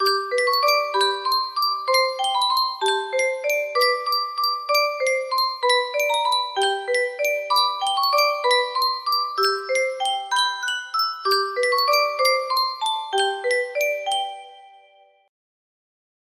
Yunsheng Music Box - Unknown Tune 1485 music box melody
Full range 60